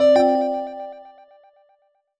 jingle_chime_10_positive.wav